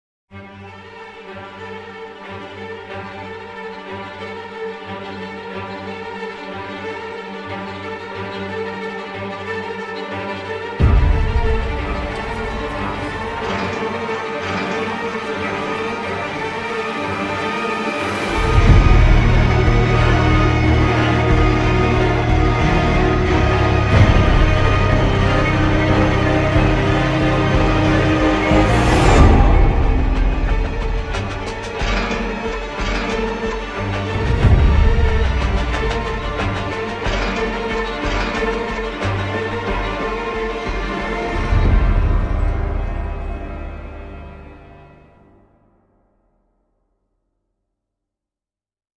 • Качество: 128, Stereo
саундтреки
инструментальные
пугающие
тревожные
страшные